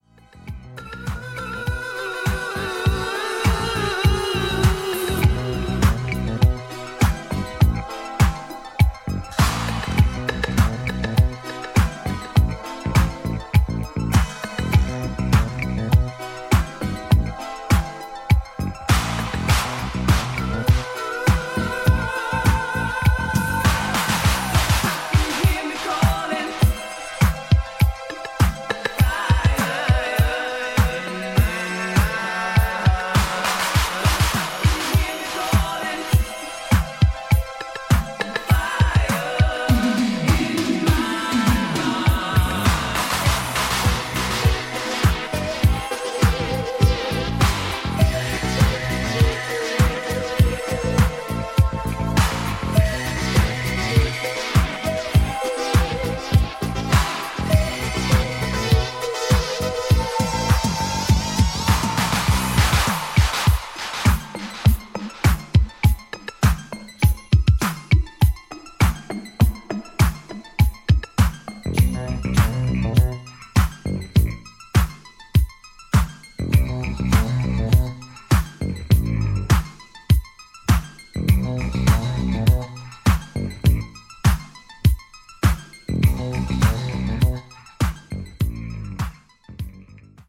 Instrumental Dub